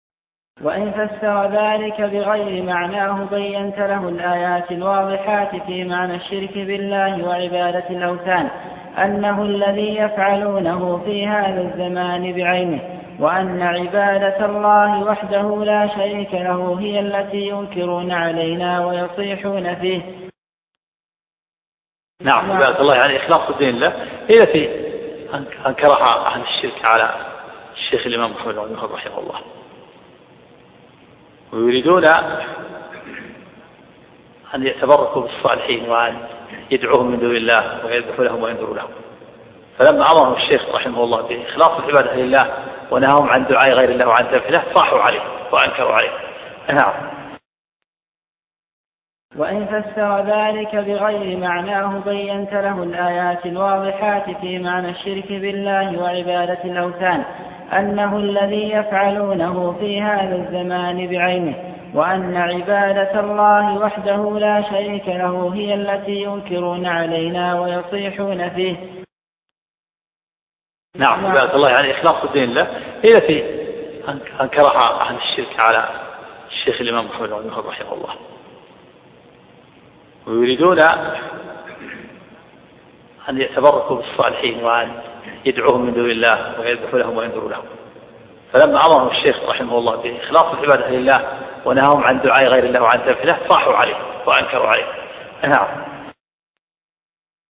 كشف الشبهات شرح الشيخ عبد العزيز بن عبد الله الراجحي الدرس 71